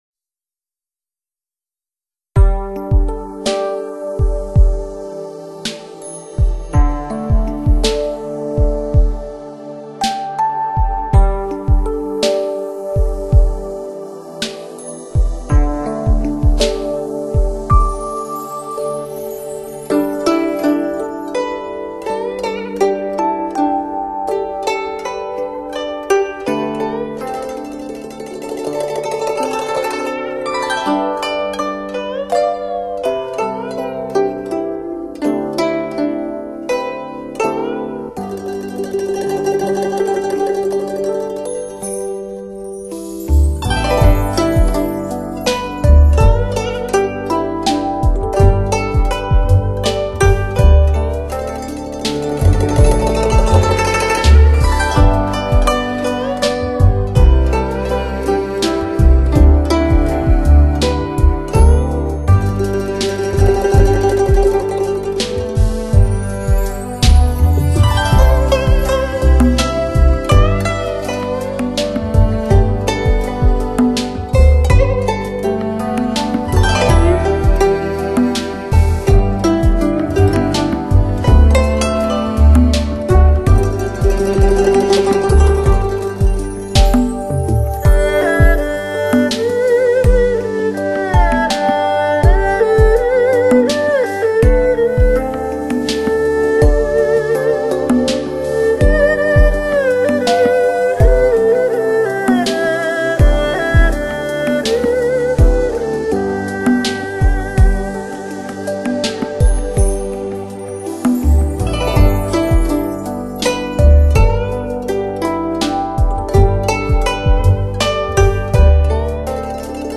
筝也叫“古筝”、“秦筝”。中国弹拨乐器。